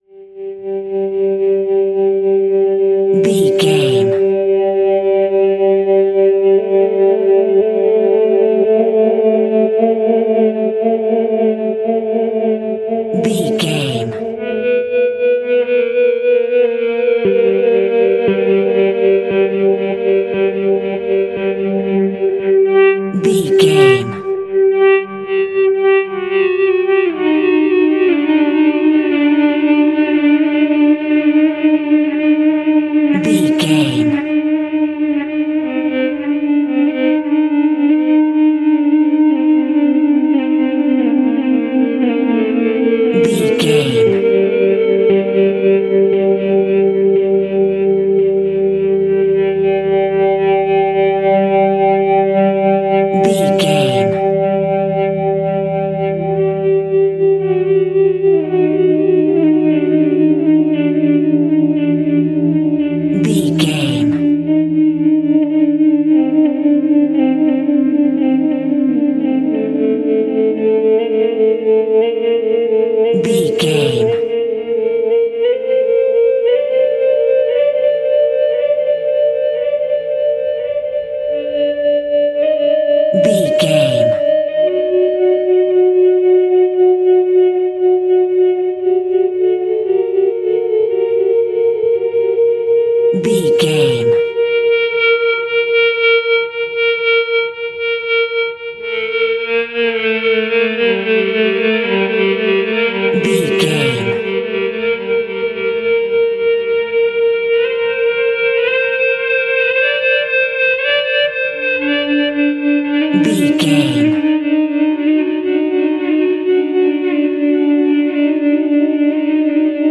Aeolian/Minor
scary
tension
ominous
dark
haunting
eerie
electric guitar
synth
ambience
pads